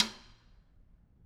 Snare2-taps_v2_rr2_Sum.wav